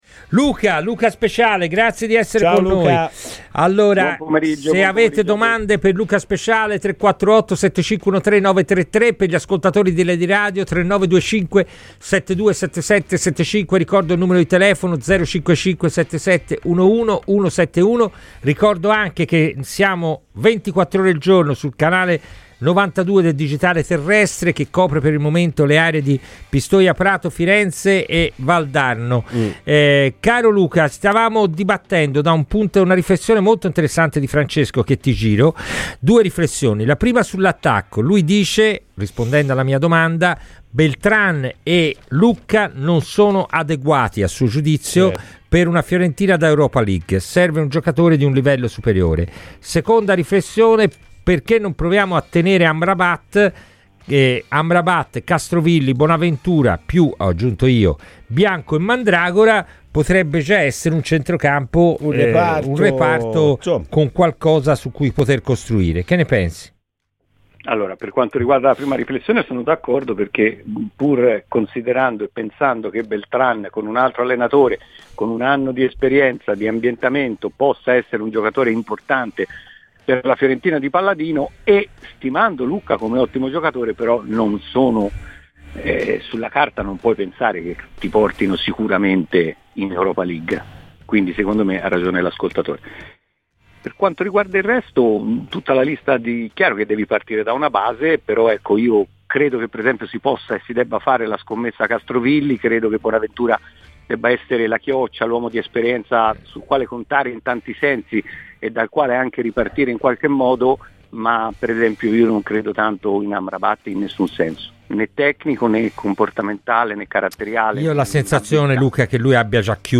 ha parlato ai microfoni di Radio FirenzeViola durante la trasmissione 'Palla al centro': Beltran e Lucca sono da Europa League? Sul centrocampo giusto tenere Amrabat?